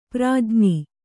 ♪ prājñi